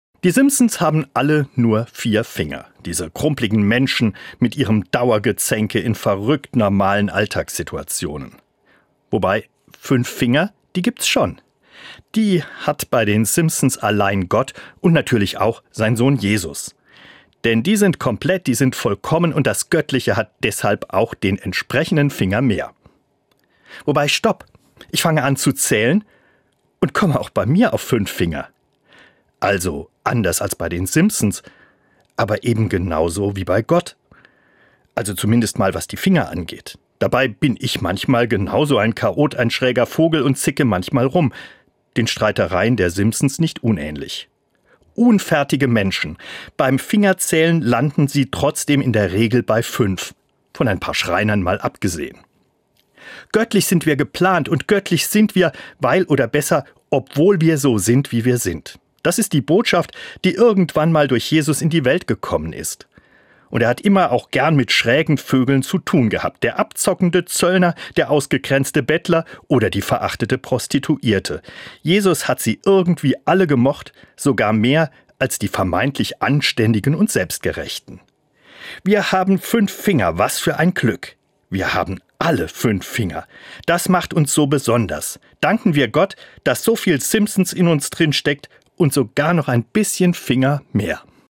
Das christliche Wort zum Alltag